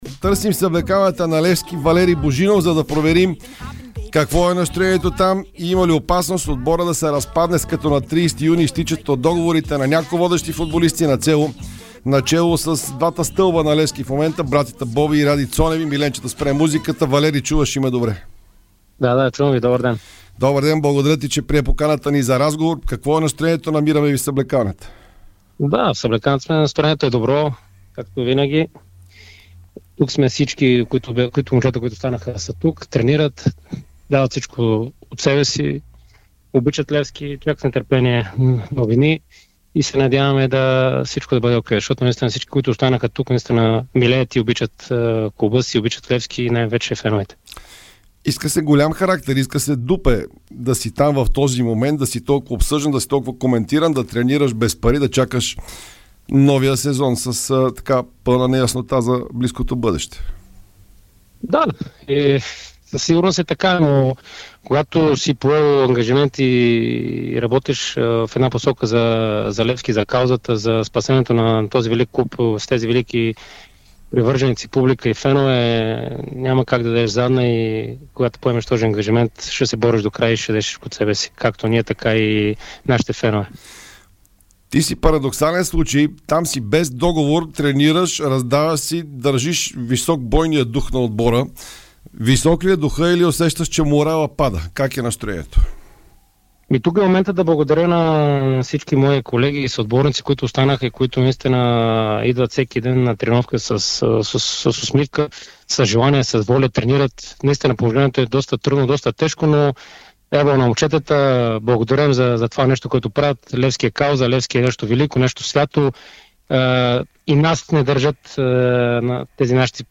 Това потвърдиха и двамата в специално интервю за Спортното шоу на Дарик радио.